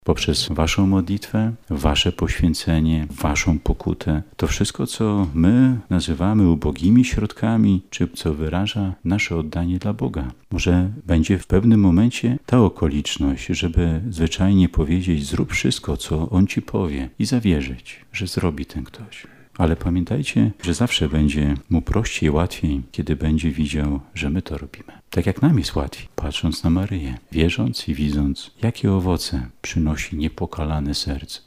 W kaplicy Instytutu Prymasowskiego Stefana Kardynała Wyszyńskiego odbyło się uroczyste posłanie kapłanów i animatorów Ruchu Światło-Życie Diecezji Warszawsko-Praskiej na wakacyjne rekolekcje.
Poprzez świadectwo swojej wiary możecie pomóc innym spotkać żywego Boga przekonywał młodzież bp Solarczyk.